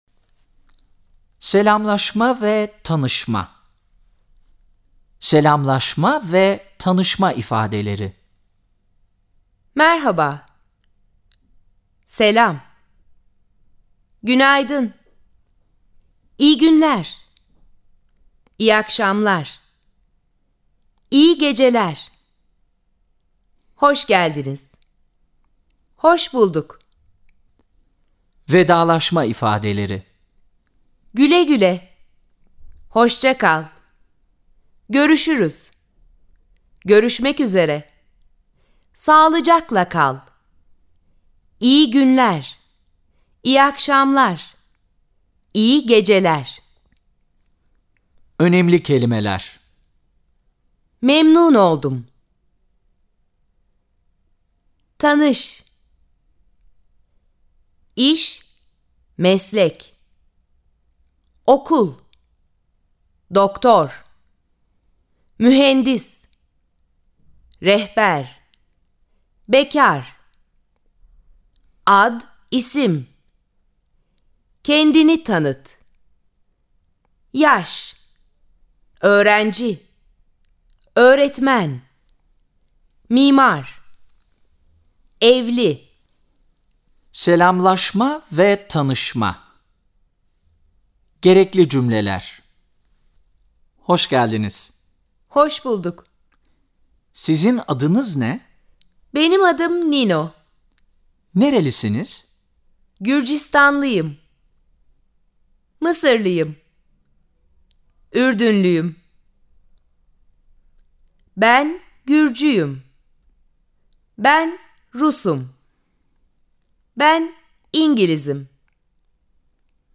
Havaalanında, otelde, restoranda, hastanede vb. birçok sosyal alanda kullanılabilecek kelimelerle birlikte örnek diyalogların da yer aldığı kitabın seslendirmeleri de uzman ses sanatçıları tarafından yapılmıştır.